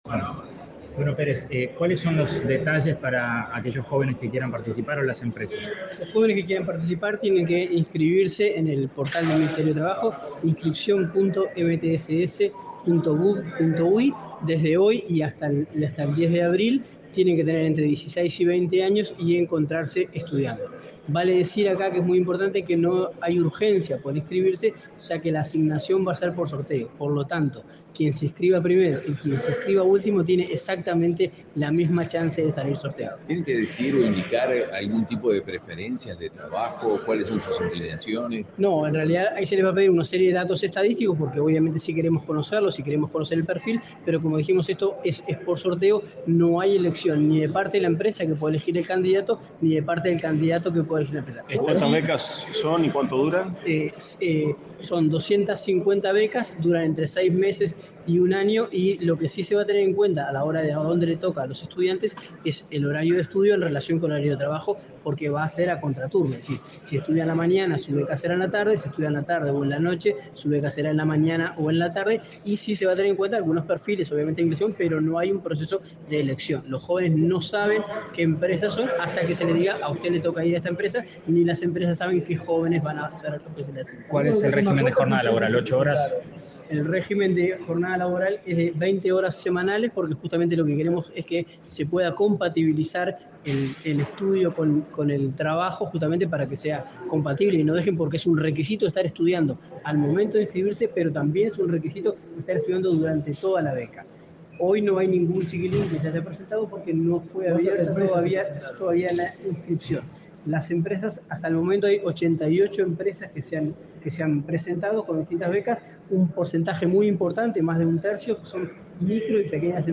Declaraciones a la prensa del director Nacional de Empleo, Daniel Pérez
Tras el evento, el director Nacional de Empleo, Daniel Pérez, realizó declaraciones a la prensa.